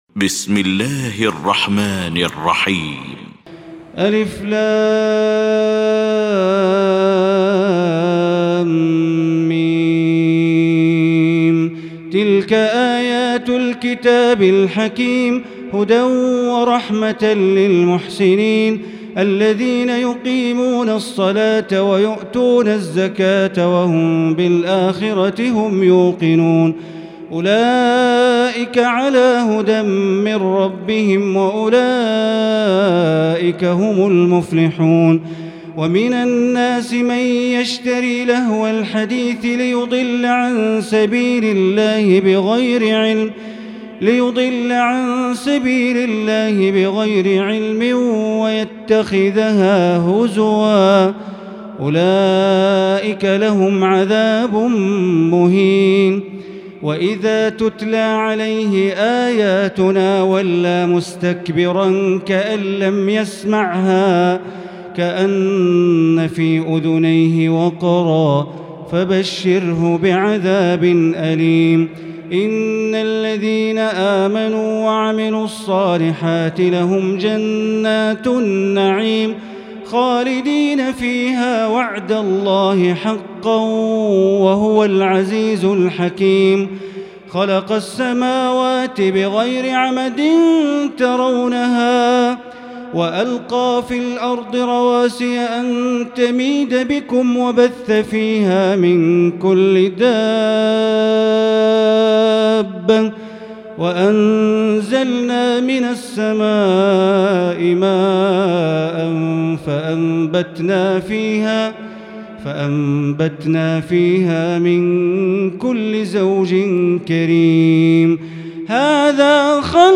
المكان: المسجد الحرام الشيخ: معالي الشيخ أ.د. بندر بليلة معالي الشيخ أ.د. بندر بليلة لقمان